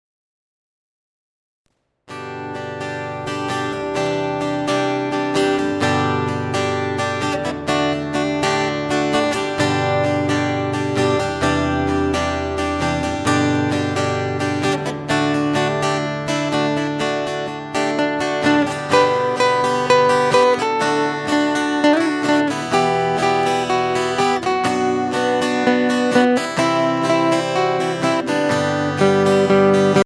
CD mit 20 Gitarren-Instrumentalstücken